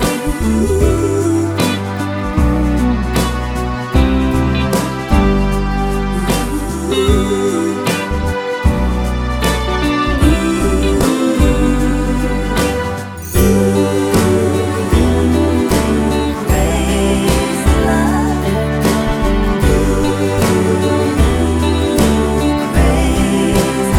no Backing Vocals Jazz / Swing 3:29 Buy £1.50